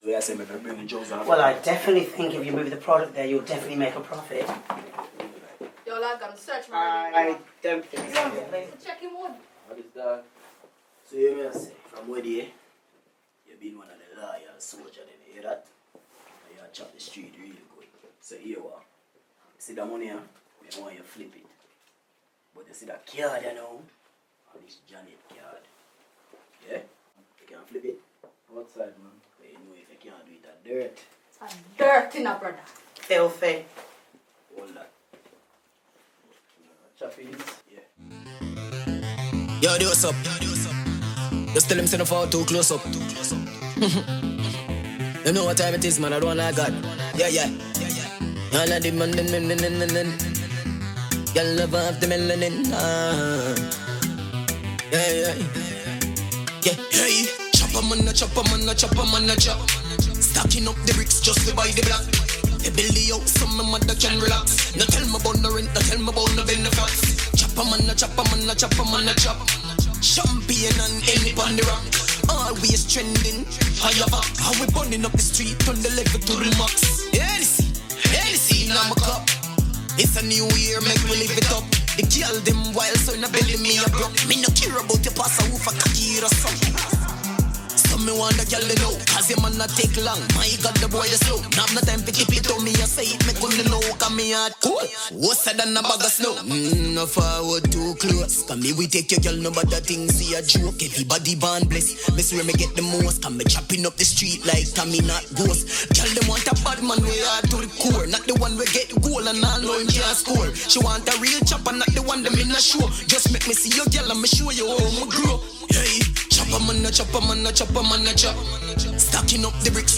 A dancehall/reggae, soul, RNB, and hip hop prodigy